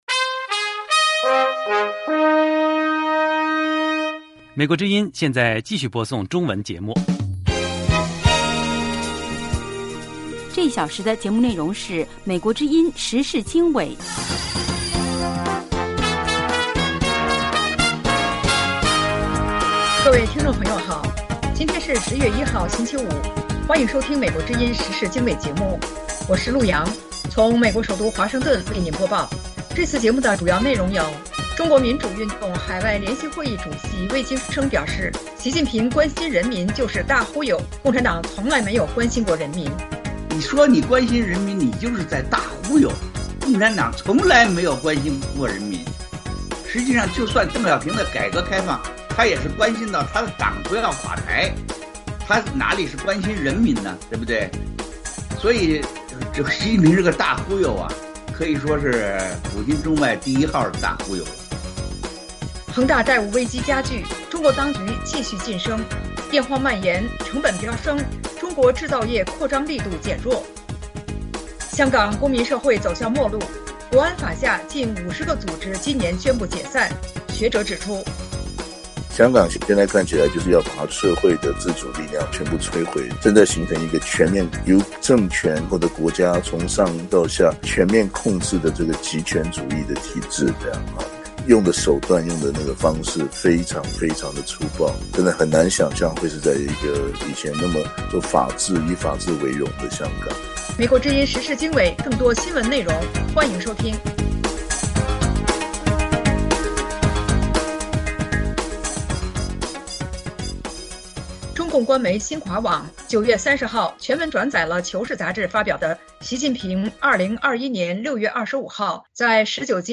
The Voice of America Window on the World and VOA Connect Programs Broadcast VOA Interview with Wei Jingsheng: So Called Xi Jinping Cares about the Chinese Is a Big Lie; the Chinese Communist Party Has Never Cared about the Chinese People